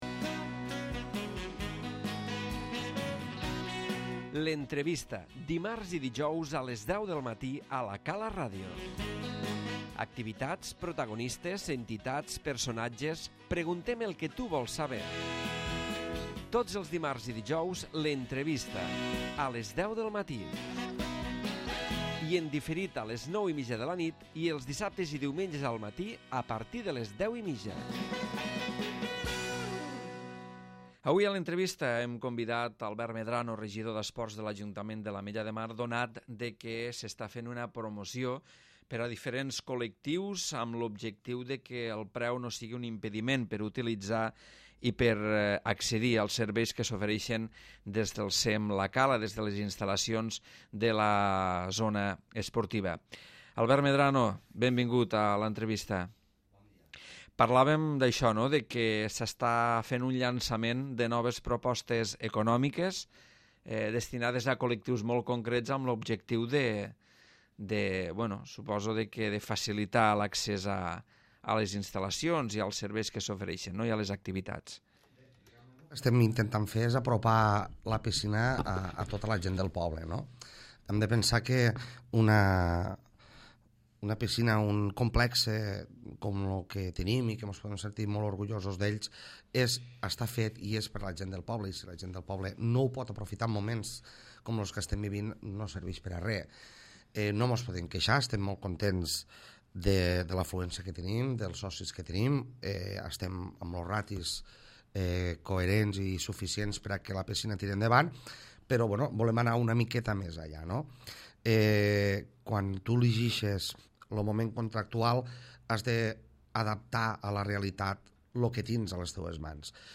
L'Entrevista
Albert Medrano, regidor d'Esports explica les diverses propostes ecopnòmiques del CEM la Cala destinades a col·lectius com els jubilats, els aturats o els joves.